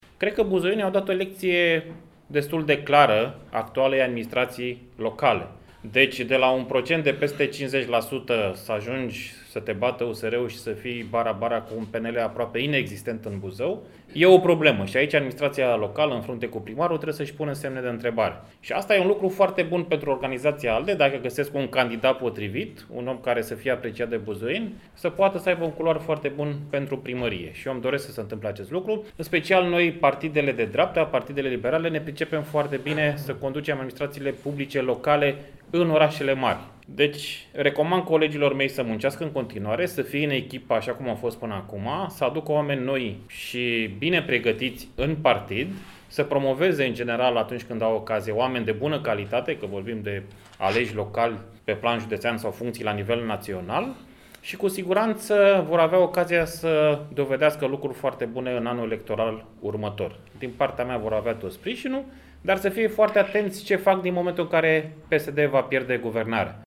Vicepreședintele ALDE, în cadrul conferinței de presă, a adus din nou în discuție rezultatele obținte de PSD pe municipiu la alegerile eruoparlamentare. Deputatul Toma Petcu s-a arătat convins că organizația județeană va reuși să aducă un candidat puternic pentru fotoliul de primar, la alegerile de anul viitor.